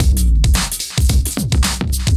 OTG_DuoSwingMixD_110a.wav